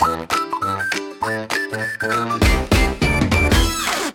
funny